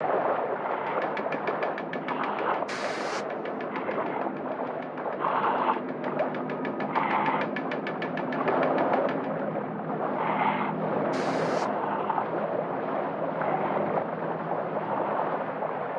cryogenic-plant.ogg